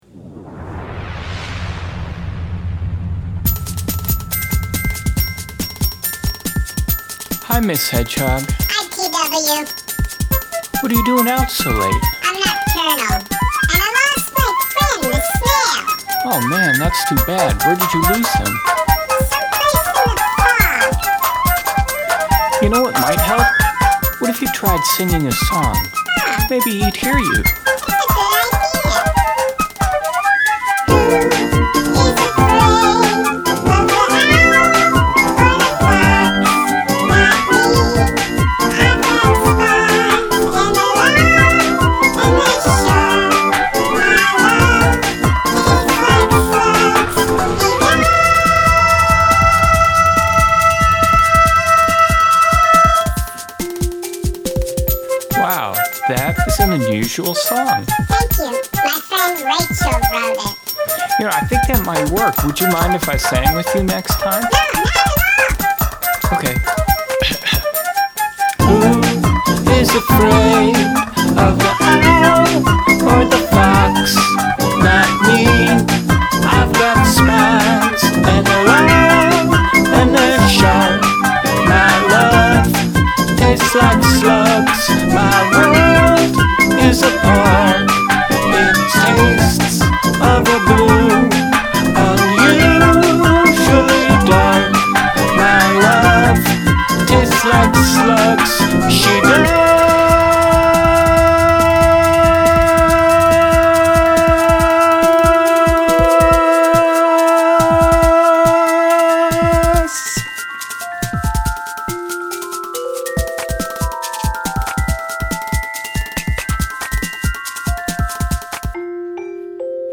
talking, singing, talking, singing, talking, singing
Actually most of this is sampled. I did all the vocals, and I played the toy xylophone, and i played the Celeste at half speed through a midi keyboard.